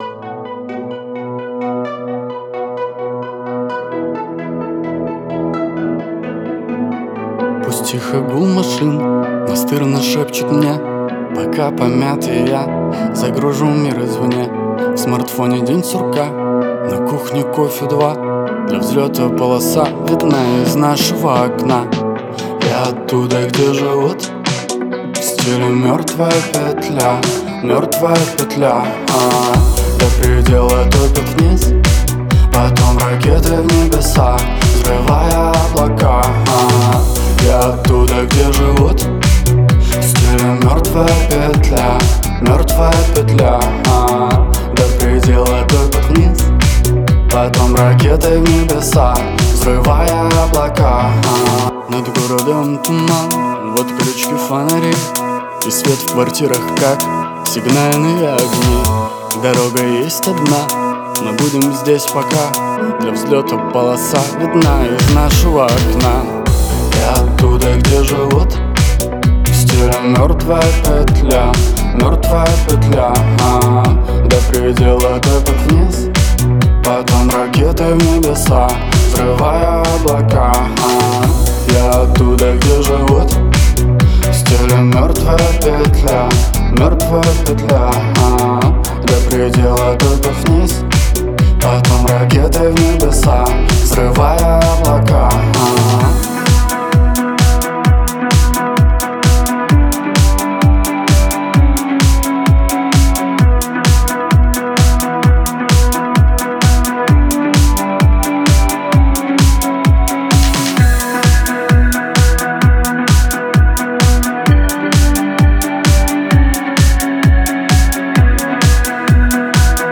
который сочетает в себе элементы хип-хопа и рэпа.